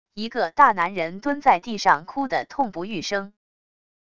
一个大男人蹲在地上哭的痛不欲生wav音频